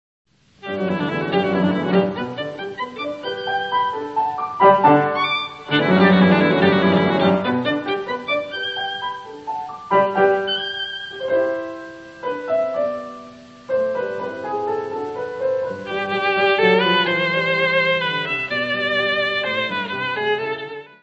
: mono; 12 cm
Music Category/Genre:  Classical Music
Allegro assai.